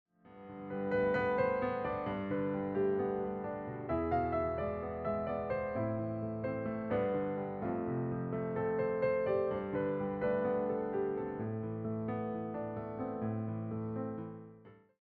reimagined as solo piano pieces